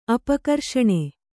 ♪ apakarṣaṇe